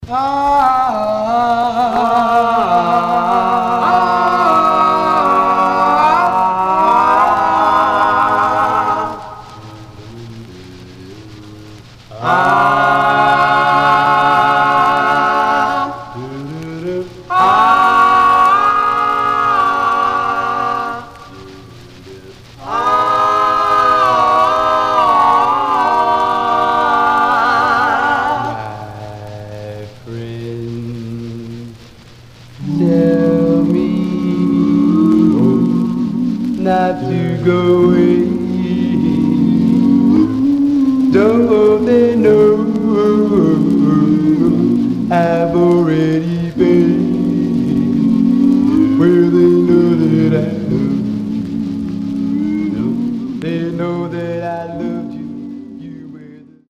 Acapella Condition: M- CONTENDERS
Stereo/mono Mono